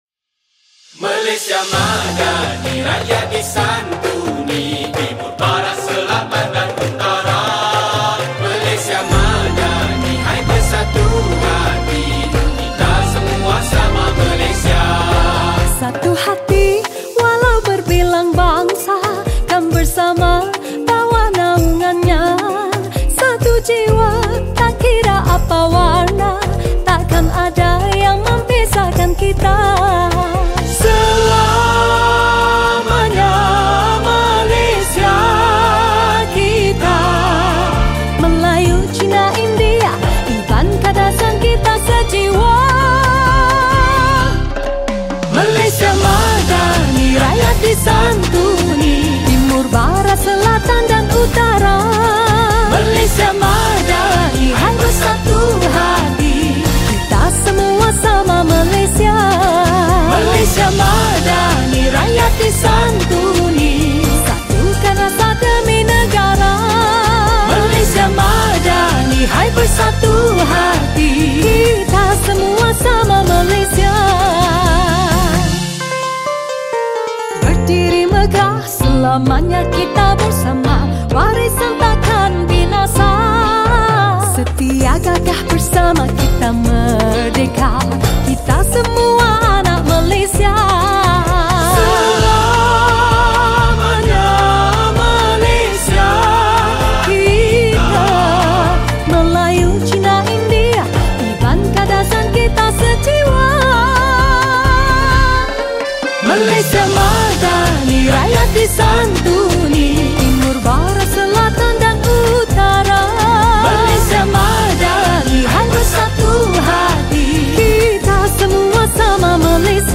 Malay Patriotic Song
Skor Angklung